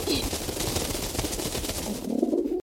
SFX鸟飞1音效下载